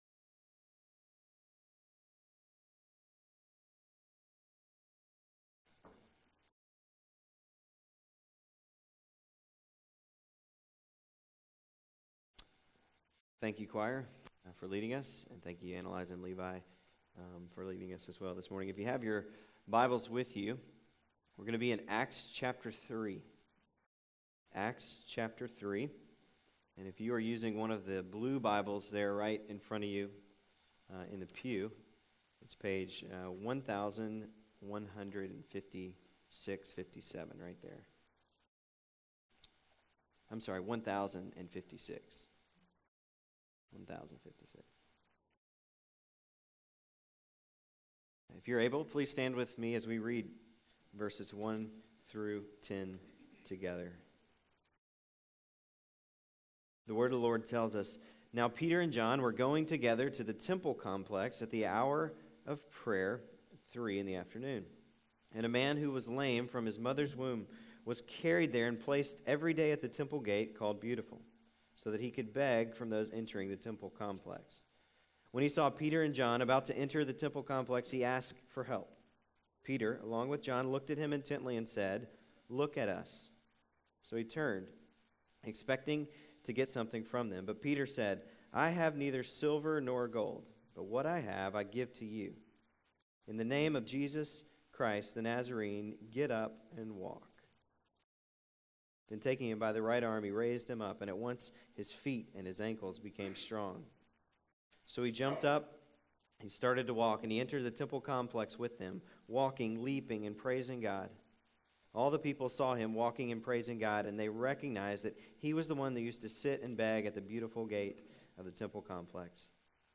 April 8, 2012 AM Worship | Vine Street Baptist Church